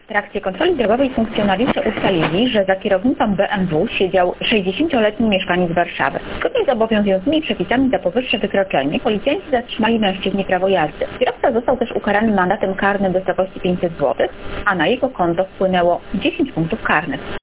Na ograniczeniu prędkości do 50 km/h zatrzymano BMW, mające na liczniku prawie 120 km/h. O niebezpiecznym kierującym mówi